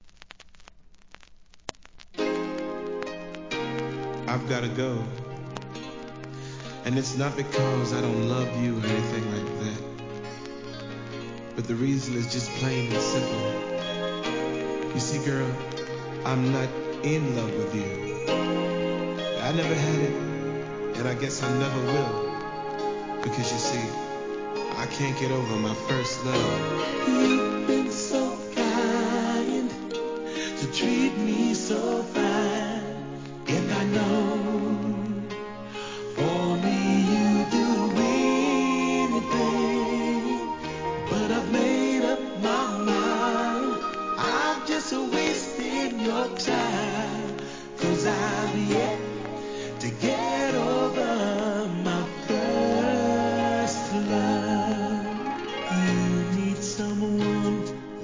SOUL/FUNK/etc...
1989年バラード♪